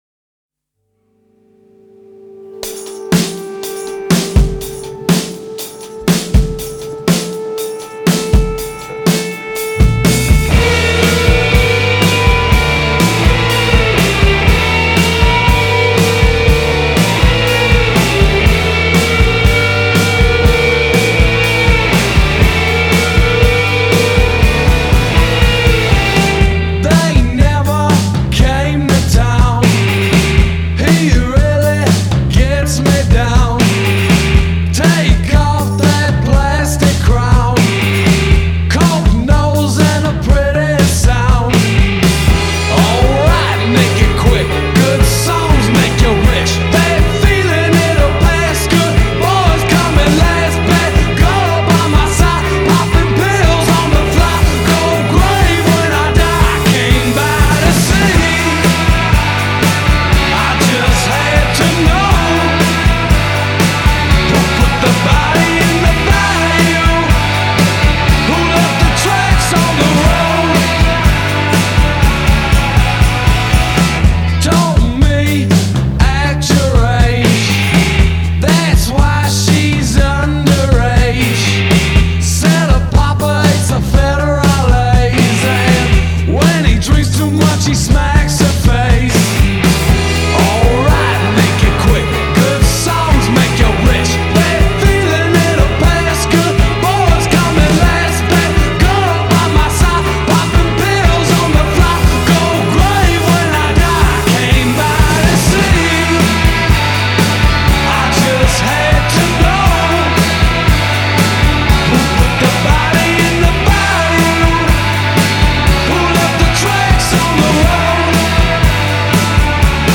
dives in and out of noisy breakdowns